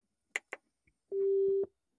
Звуки аудио-колонки
Звук нажатия кнопки включения